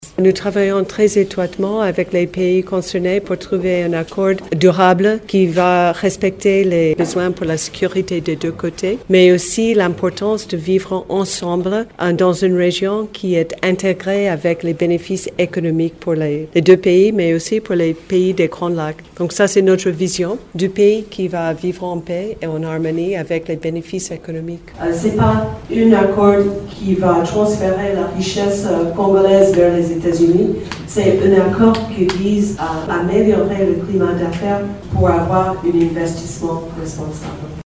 L’Ambassadrice américaine en RDC, Lucy Tamlyn, a apporté cette précision au cours d’une rencontre organisée mardi 24 juin au Centre culturel américain à Kinshasa, par le Forum d’études en relations internationales du Congo (FERIC).